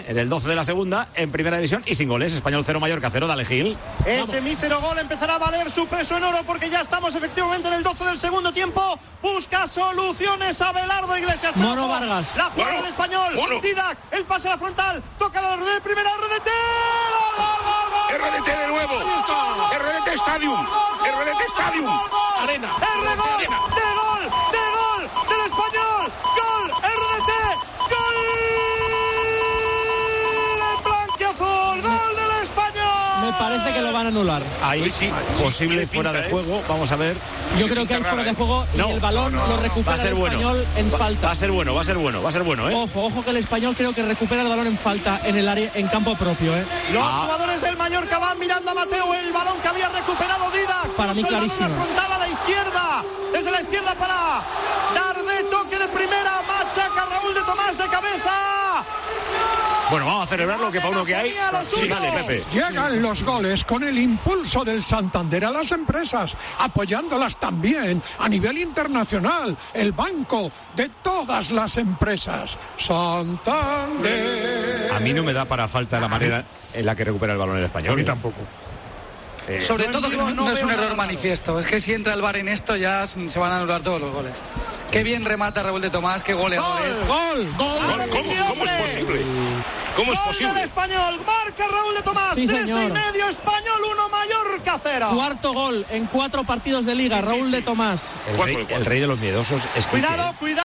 Así sonó el gol perico con narración